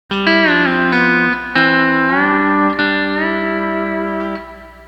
Steel Guitar Tab / Lessons
E9th - Hammer Off - Key of C Tab